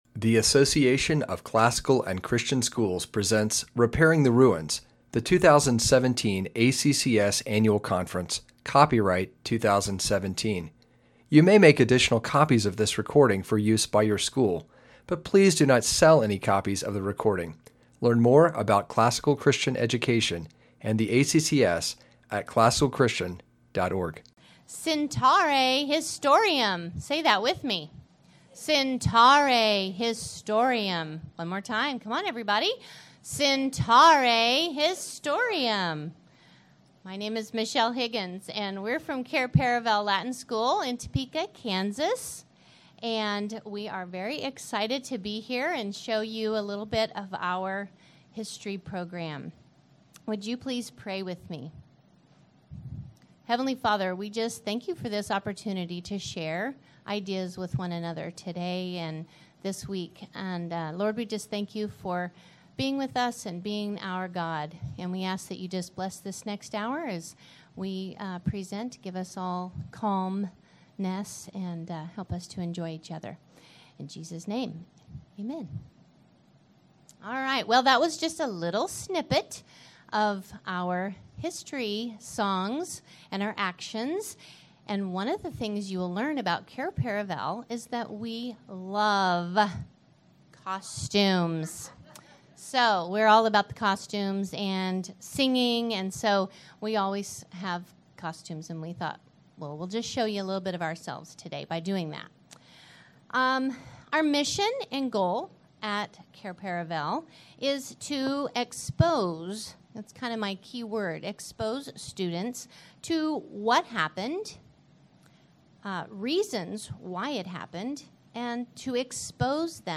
2017 Workshop Talk | 0:35:25 | All Grade Levels, Curriculum Overviews
Speaker Additional Materials The Association of Classical & Christian Schools presents Repairing the Ruins, the ACCS annual conference, copyright ACCS.